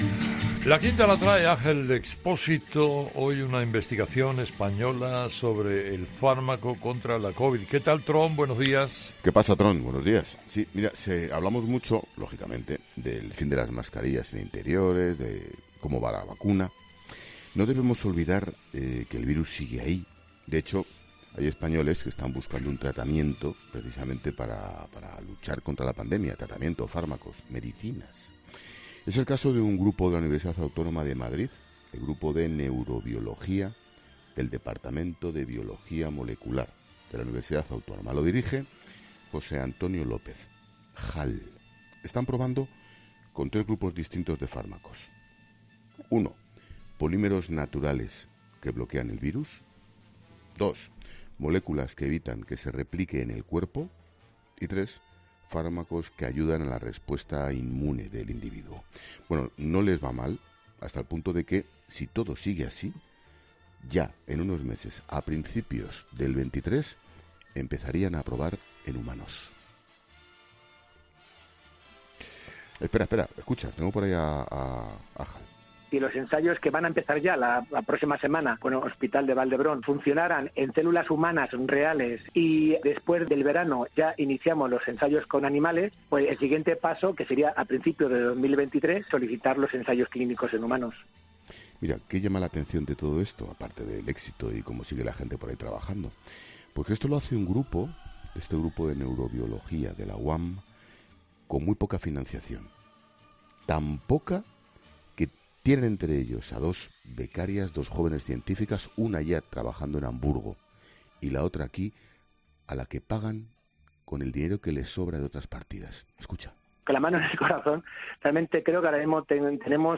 Expósito, que ha reproducido audios de uno de los responsables del grupo, asegura que "no les va mal, hasta el punto de que si todo sigue así, en unos meses, a principios del 23, empezarán a probar en humanos", lo que sin duda sería una noticia reseñable y de la que sentirse orgullosos.